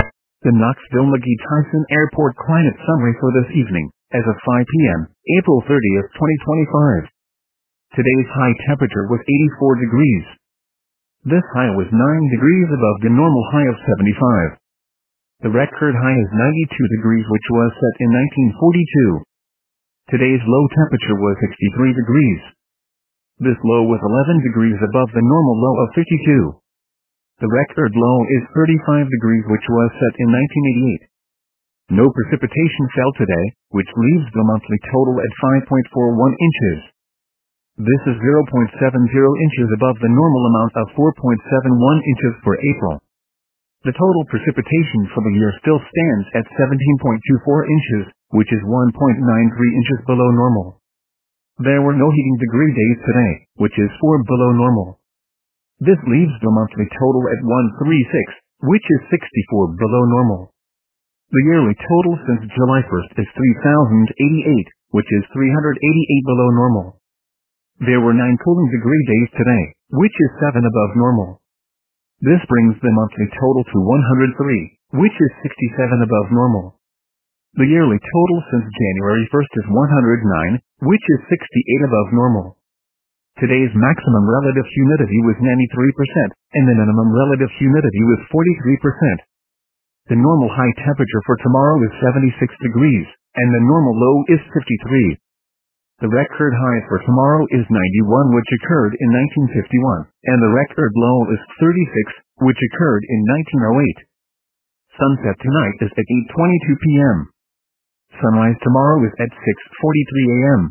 MRX Weather Radio Forecasts